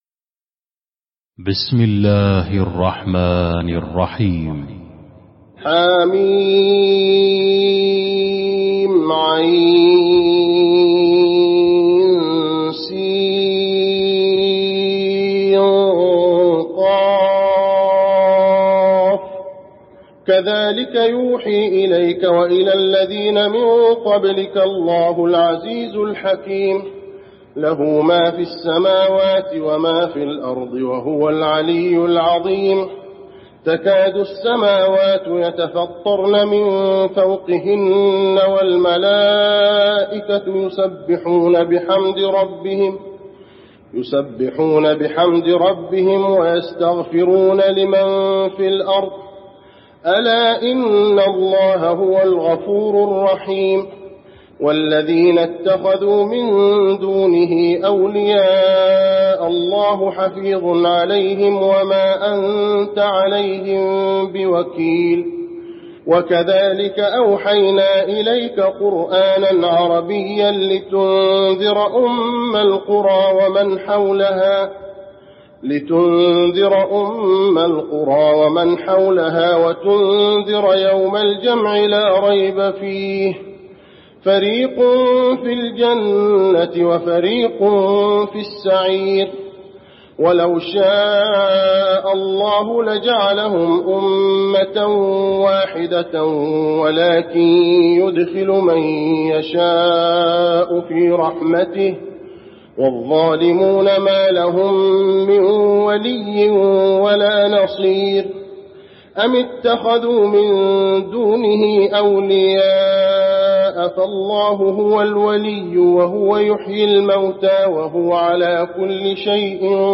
المكان: المسجد النبوي الشورى